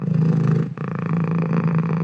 purr2.ogg